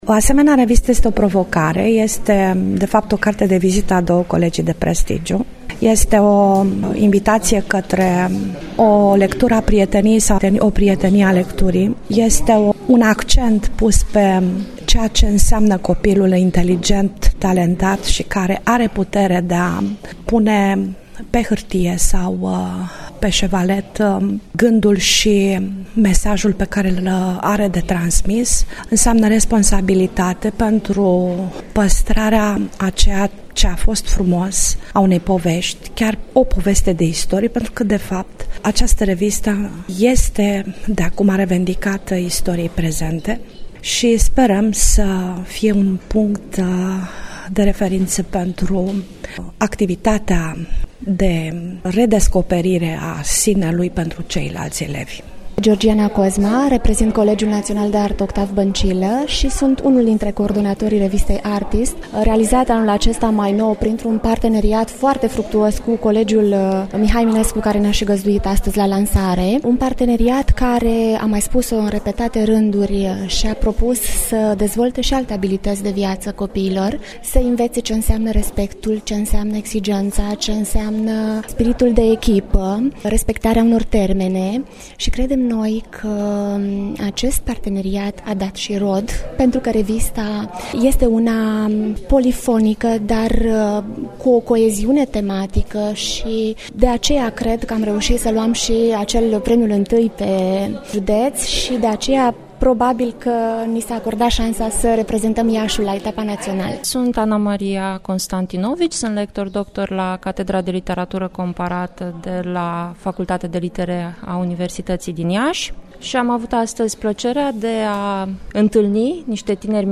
(REPORTAJ) O nouă revista școlară de excepție